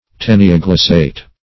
Search Result for " taenioglossate" : The Collaborative International Dictionary of English v.0.48: Taenioglossate \Tae`ni*o*glos"sate\, a. (Zool.) Of or pertaining to the Taenioglossa.